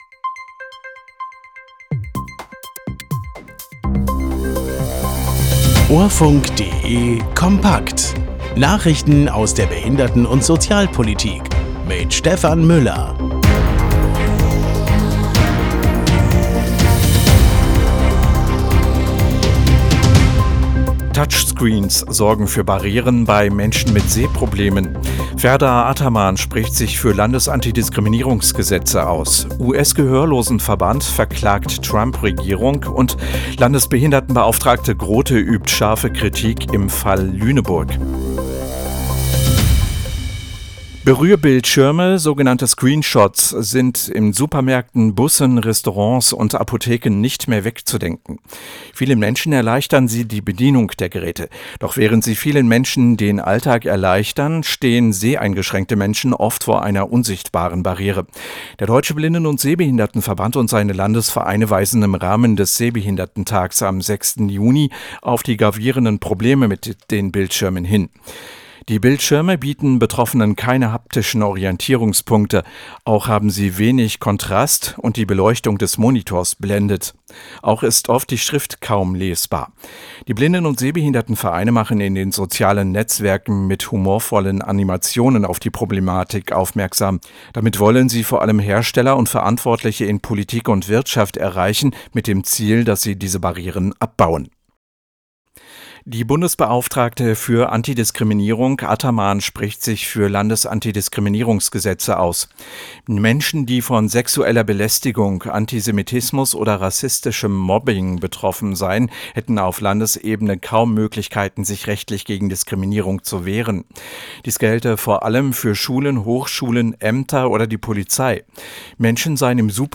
Nachrichten aus der Behinderten- und Sozialpolitik vom 04.06.2025